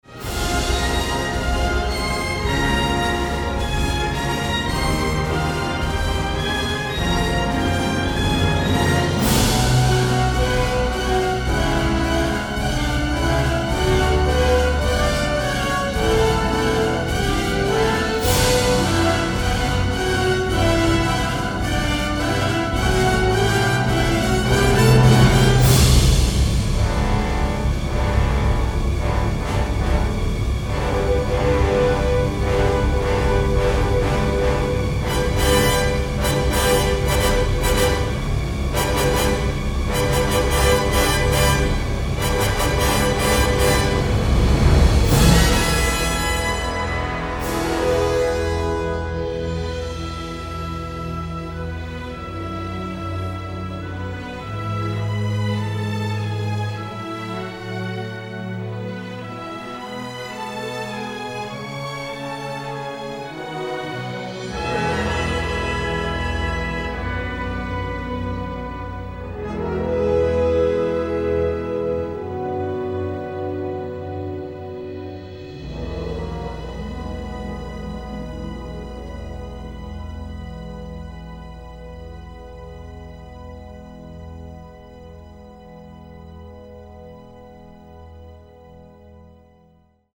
propulsive orchestral score
Teeming with smoldering drama and explosive action